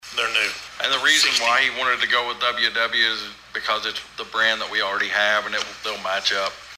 The Board of Osage County Commissioners convened for a brief and productive meeting at the fairgrounds on Monday morning to discuss an assortment of items.
District One Commissioner Anthony Hudson and District Two Commissioner Steve Talburt